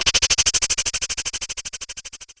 Glitch FX 15.wav